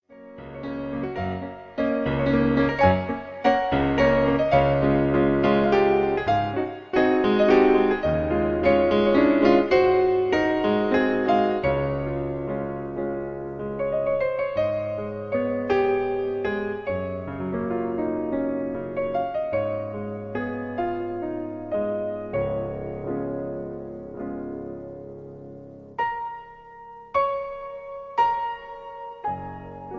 Reizende Arie